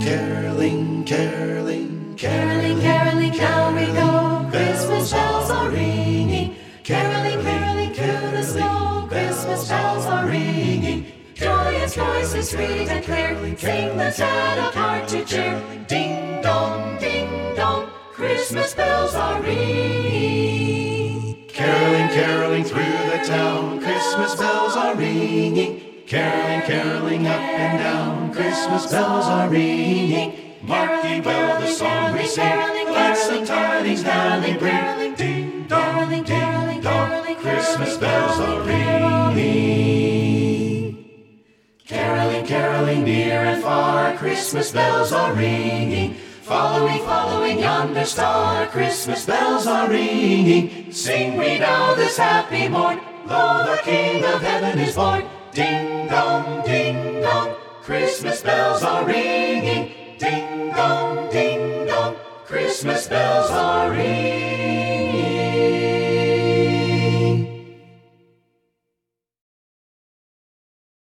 a cappella album.